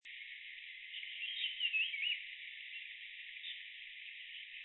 南投縣 水里鄉 大觀發電廠
次生林
雄鳥歌聲
Denon Portable IC Recorder
Sennheiser 型號 ME 67